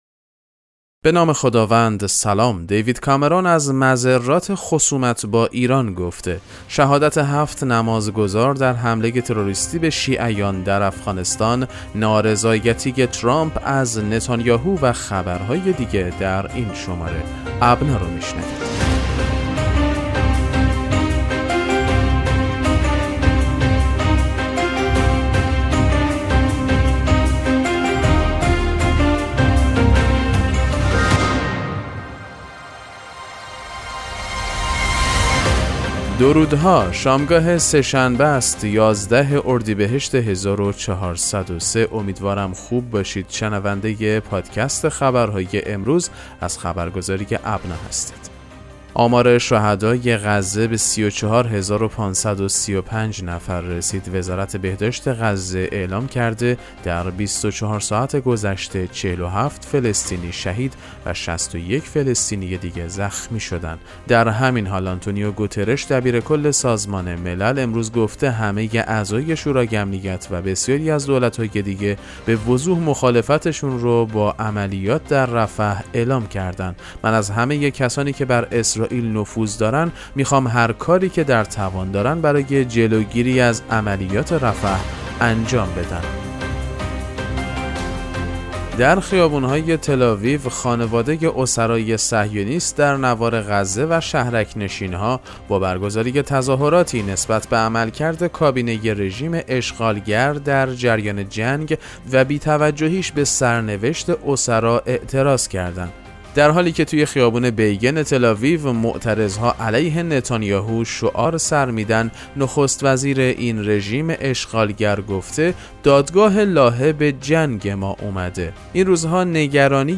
پادکست مهم‌ترین اخبار ابنا فارسی ــ 11 اردیبهشت 1403